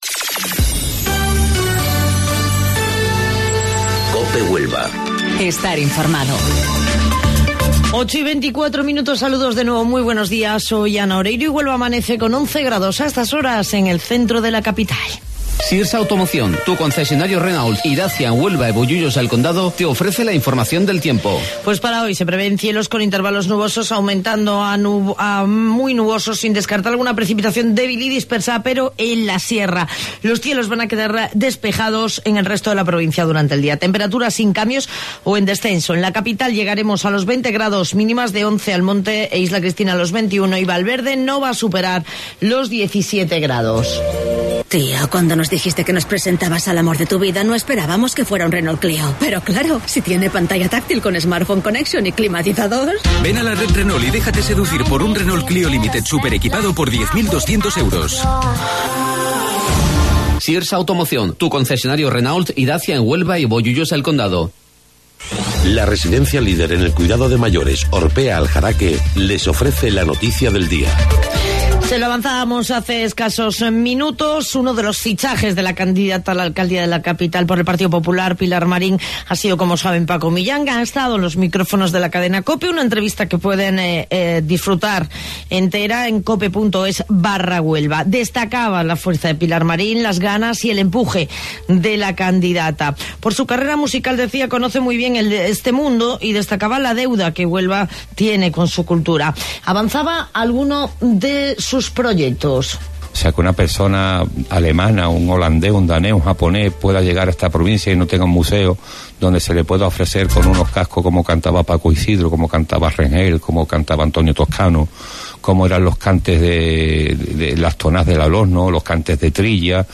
AUDIO: Informativo Local 08:25 del 9 de Abril